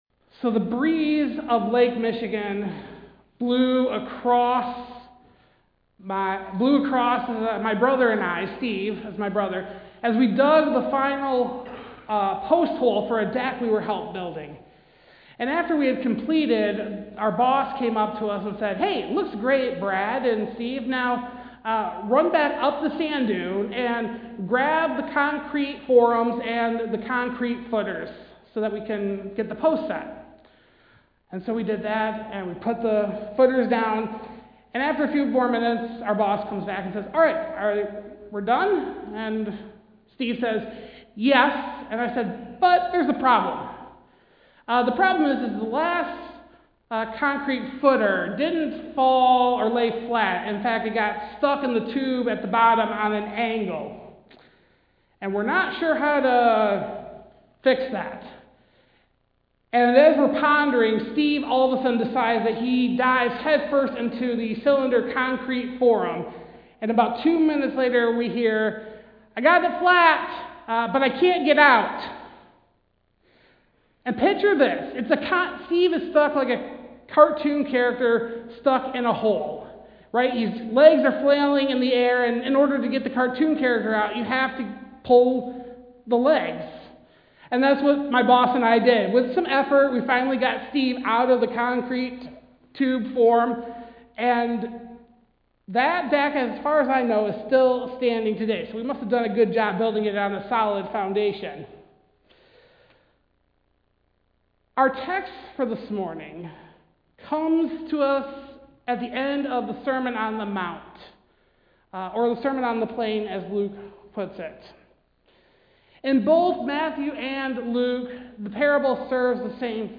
Passage: Matthew 7:24-27, Luke 6:46-49 Service Type: Sunday Service